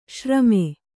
♪ śrame